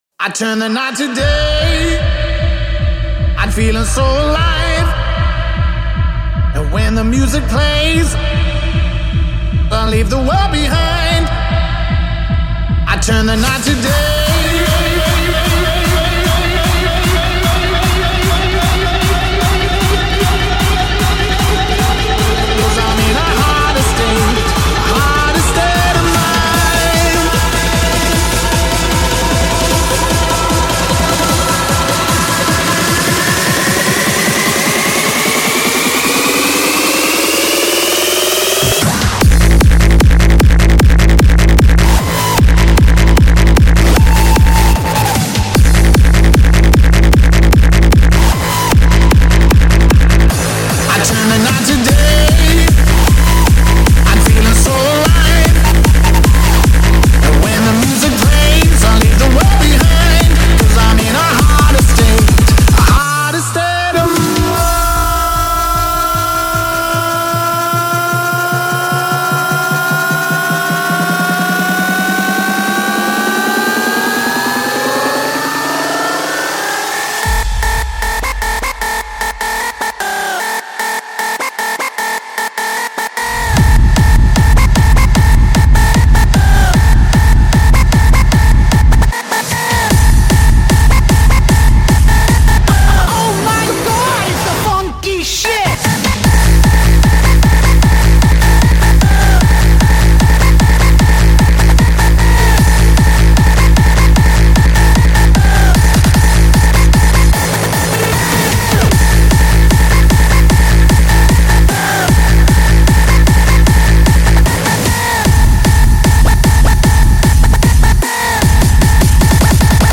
Quarantine Livestreams Genre: House